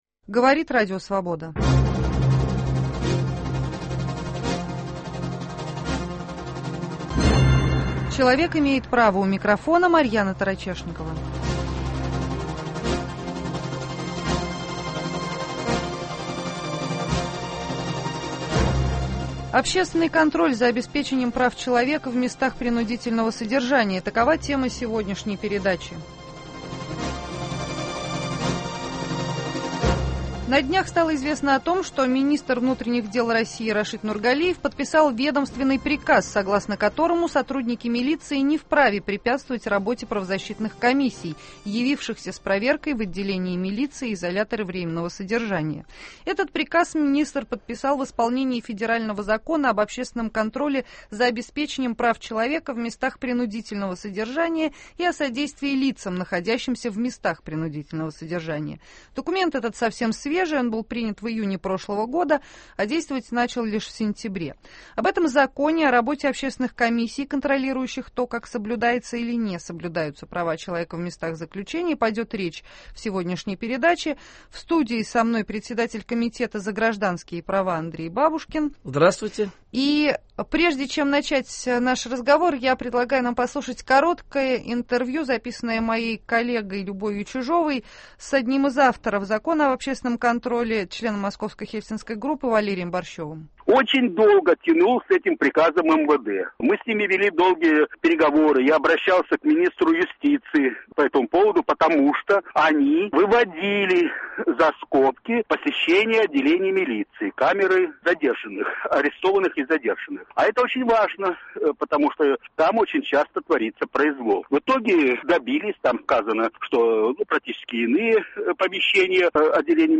Общественный контроль за обеспечением прав человека в местах принудительного содержания. В студии РС